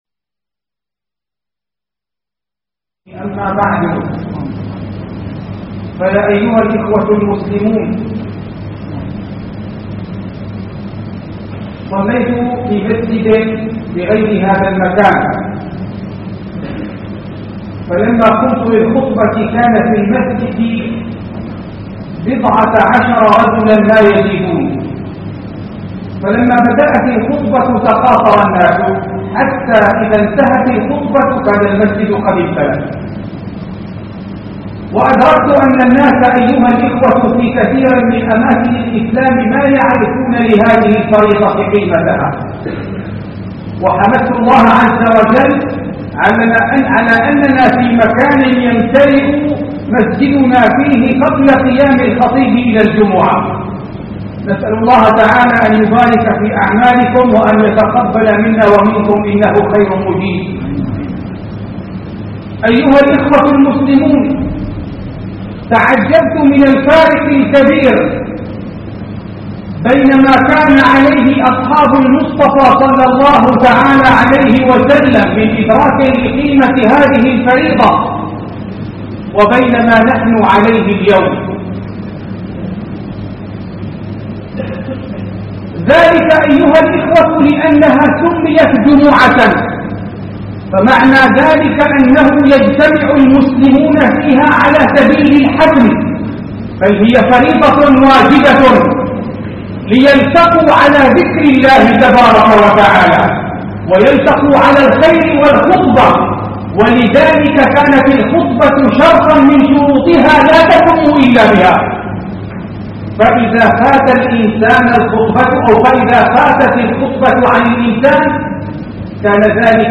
محاضره بعنوان صلاة الجمعة فضلها و أحكامها